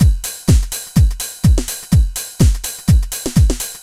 Index of /musicradar/retro-house-samples/Drum Loops
Beat 09 Full (125BPM).wav